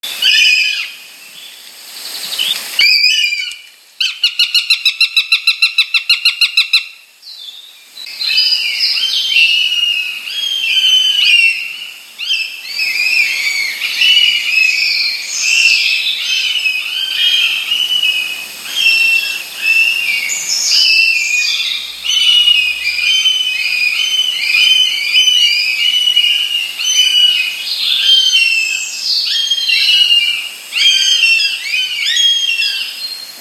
Uliul porumbar (Accipiter gentilis)
Ascultă strigătul uliului prin frunziș!
Este un uliu mare și puternic, stăpân al pădurii bătrâne. Țipetele lui ascuțite se aud de departe, mai ales în sezonul de cuibărit.
Uliu-porumbar.m4a